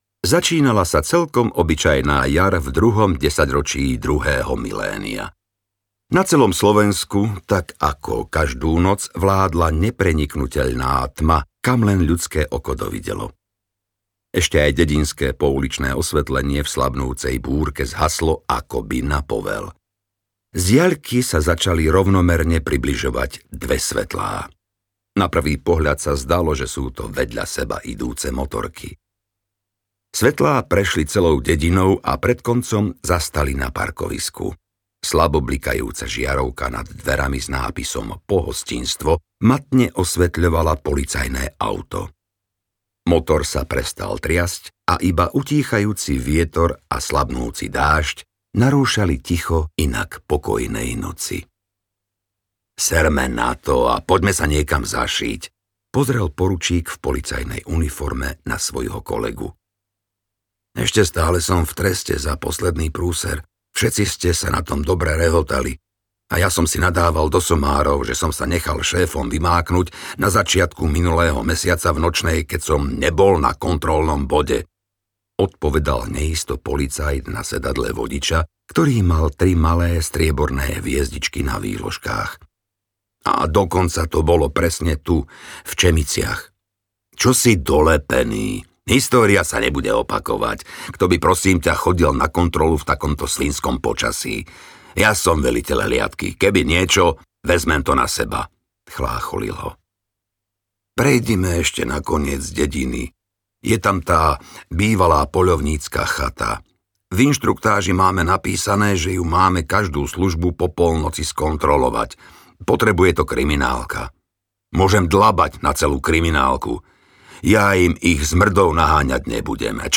Polícia v rukách mafie audiokniha
Ukázka z knihy